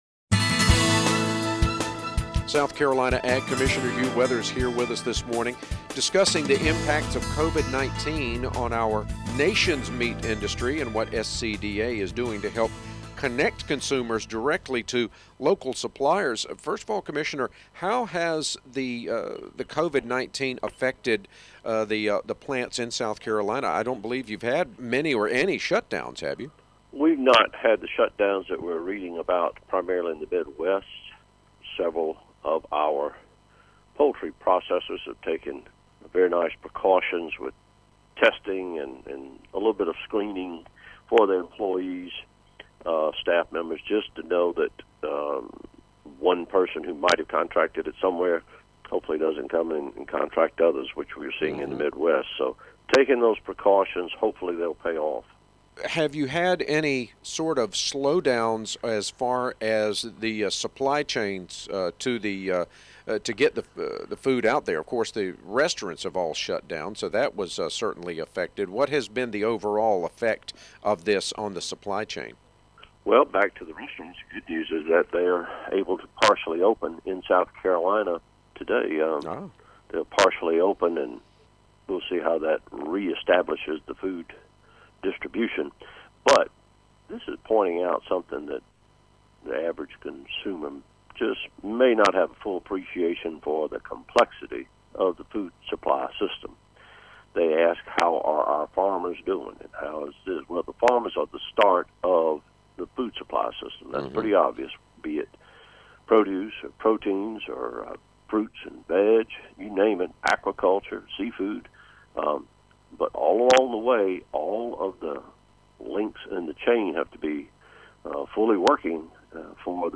Every week Commissioner Hugh Weathers answers questions about what’s going on with agriculture in our state with The Southern Farm Network.